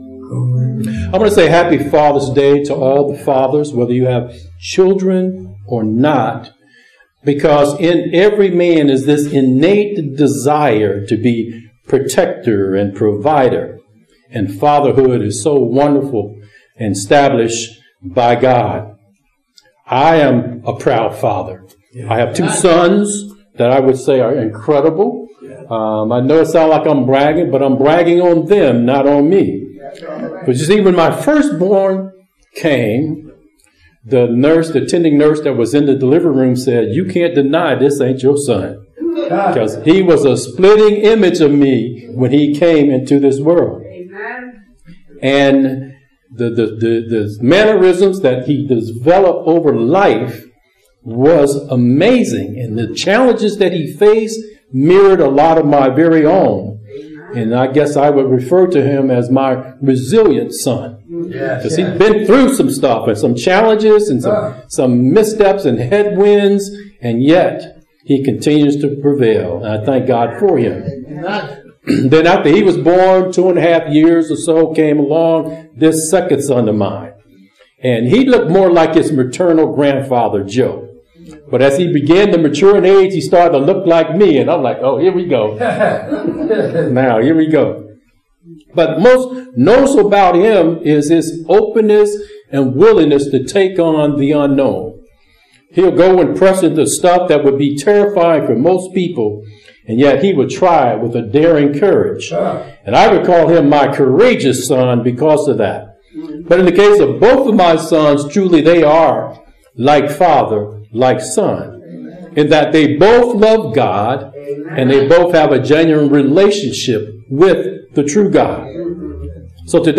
Sermons | Truth Teaching Ministries